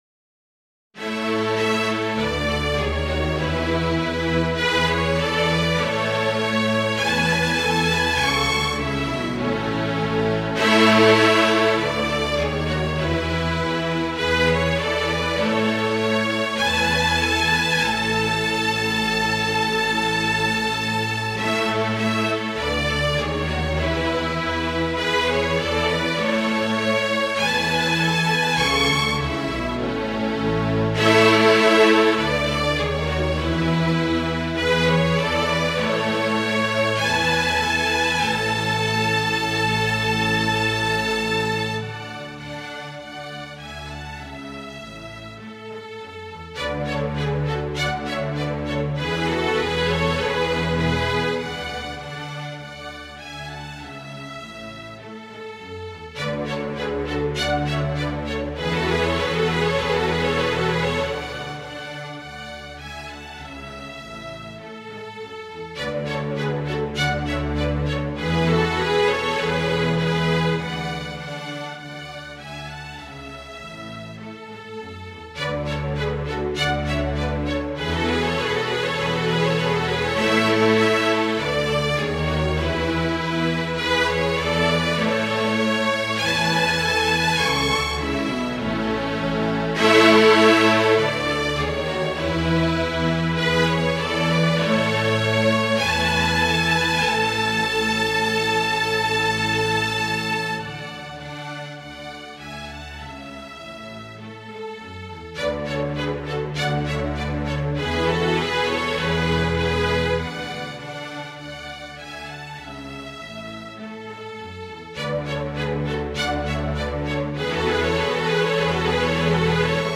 A scenario was published in the Mercure de France (June 1751: 2/160-66), and parts from the score (vln I, vln II and basso), written by Robert des Brosses (1719-1799), were also published.
The recordings are MIDI mockups, computer-generated using the playback software NotePerformer and performed as the music is written.
(MIDI mockup of no. 2, Air Niais en Rondeau)